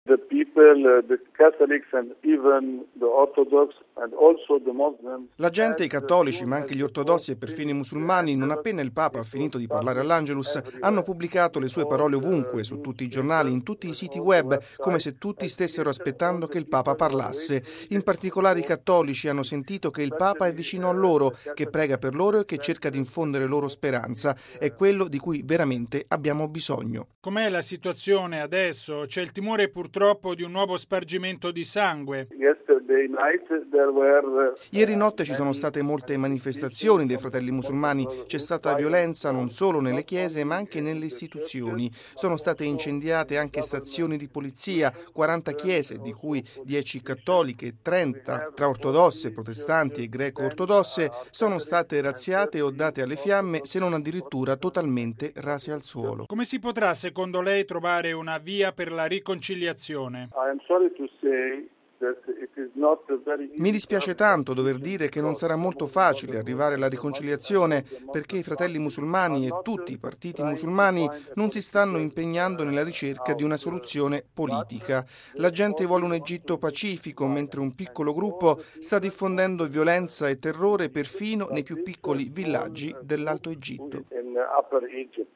raggiunto telefonicamente in Egitto